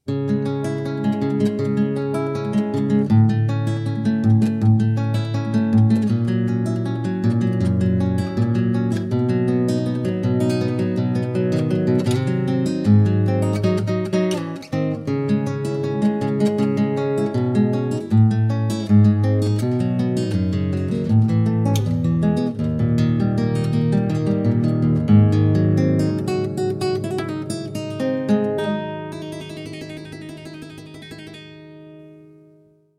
Guitar
Normal Speed